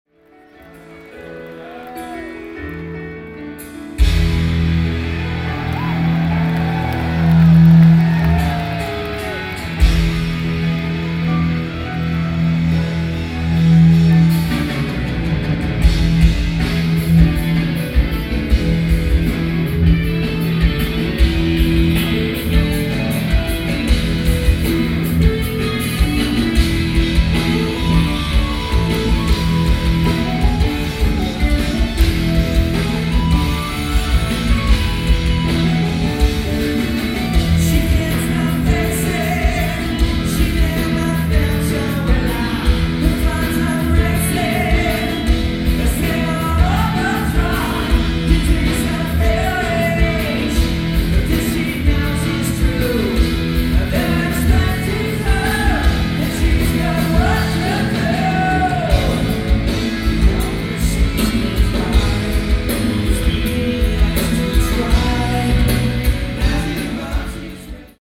Source: Audience (Master DAT)
Venue: Tower Theater
Equipment List: Sony PCM-M1, Soundman OKM II RKS mics
A good audience recording.